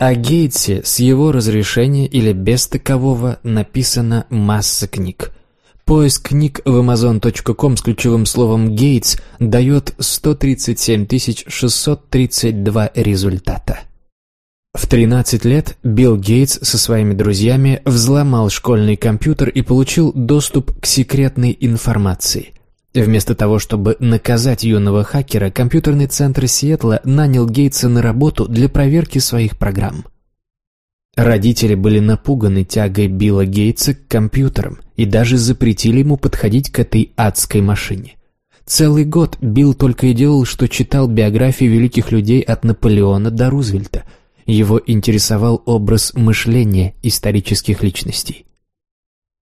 Аудиокнига Билл Гейтс. Секреты успеха | Библиотека аудиокниг